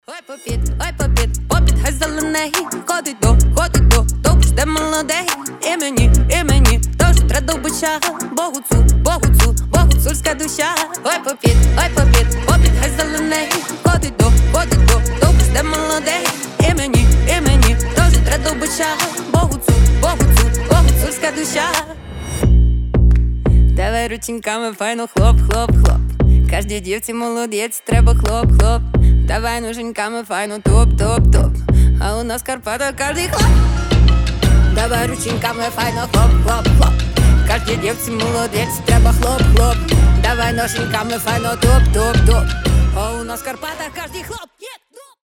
• Качество: 320, Stereo
фолк
озорные